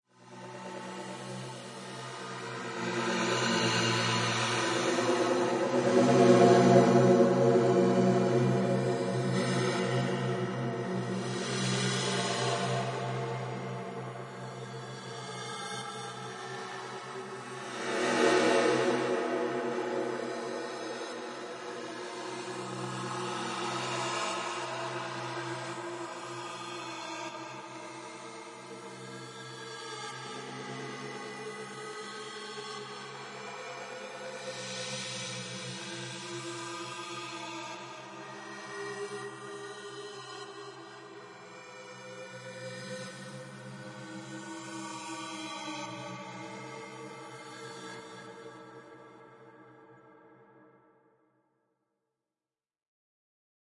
黑暗氛围
描述：一只黑暗的怪异的无人机，在Audacity和paulstretch一起乱搞。
标签： 悬念 氛围 环境 焦虑 无人驾驶飞机 黑暗 令人毛骨悚然 邪恶 戏剧 怪异 恐怖 兴奋 气氛 戏剧 闹鬼
声道立体声